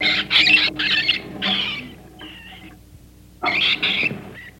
Rats Squeaking, Chattering